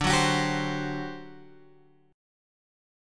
D11 chord {10 9 x 11 8 8} chord